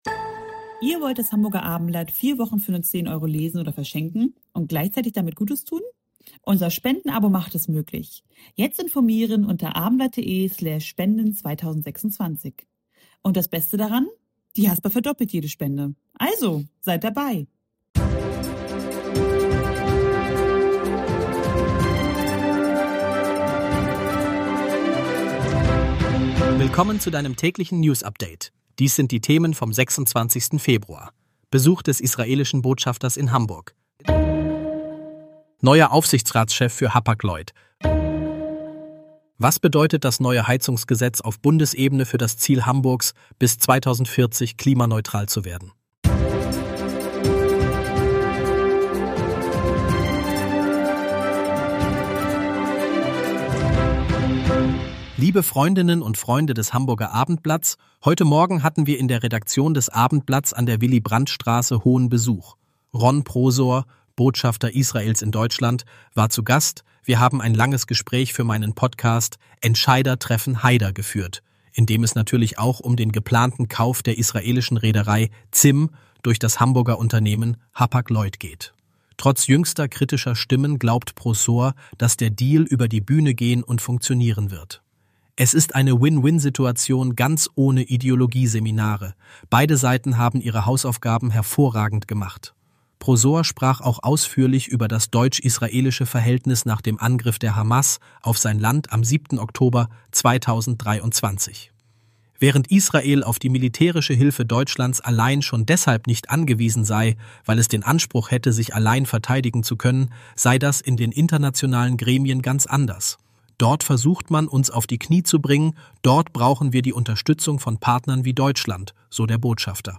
Hamburg-News - der aktuelle Nachrichten-Überblick um 17 h